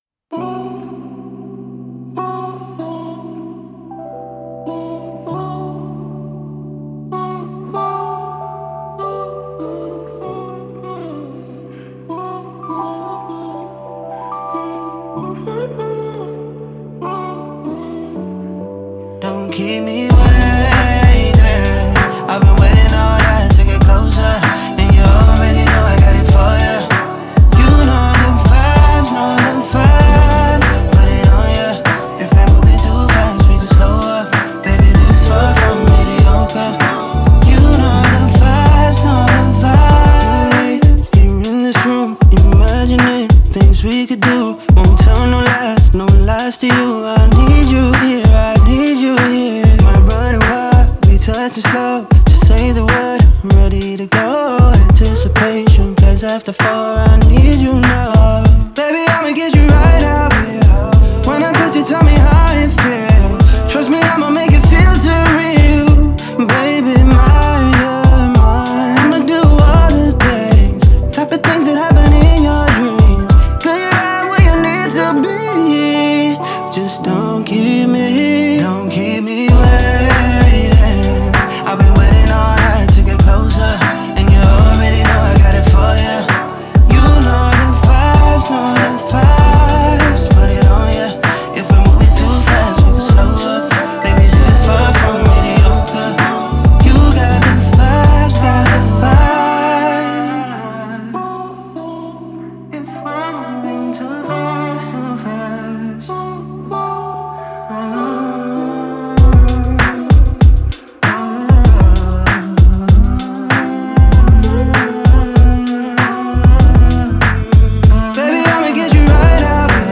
яркая и мелодичная песня